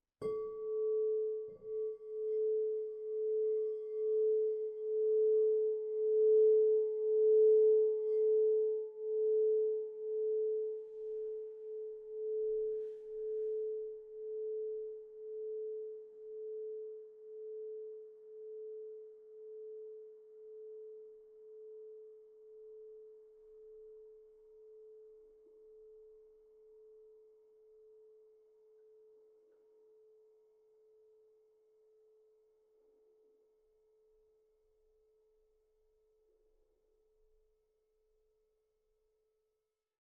Meinl Sonic Energy 9" color-frosted Crystal Singing Bowl A4, Dunkelblau, 440 Hz, Stirnchakra (CSBC9A)
Die farbig mattierten Meinl Sonic Energy Kristallklangschalen aus hochreinem Quarz erzeugen mit ihrem Klang und Design eine sehr harmonische Aura.…